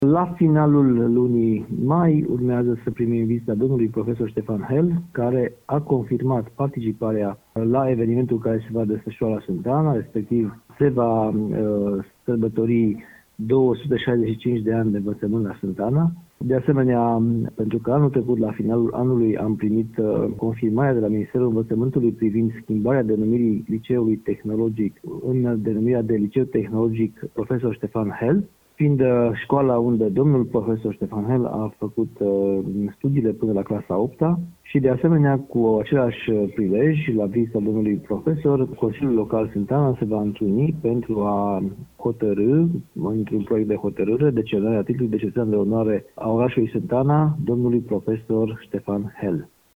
Consiliul local va vota acordarea acestei distincţii iar înmânarea diplomei va fi făcută, în luna mai, spune primarul oraşului arădean, Daniel Sorin Tomuţa.
ARAD-00-DANIEL-SORIN-TOMUTA-PRIMAR-SANTANA-LU21MART.mp3